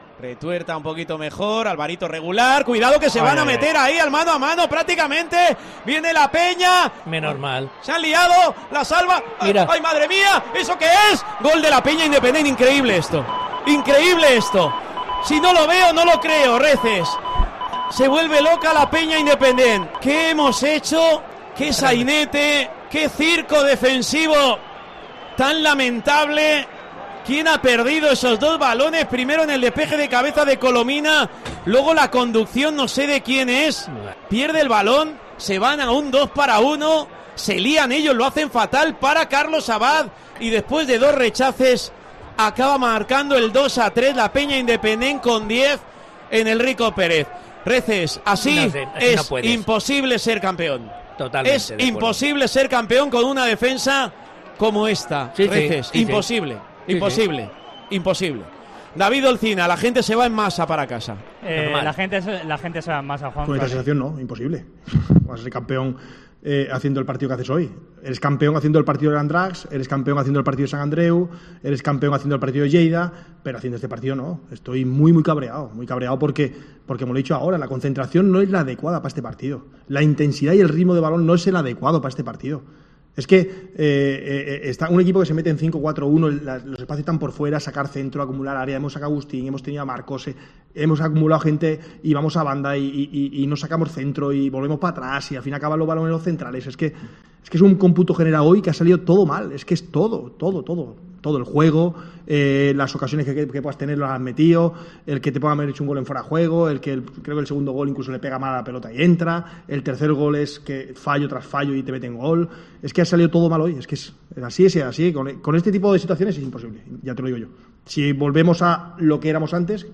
Así te hemos contado la dura derrota del Hércules en el Tiempo de Juego de Cope Alicante: con gol en el descuento y con un rival de descenso en inferioridad numérica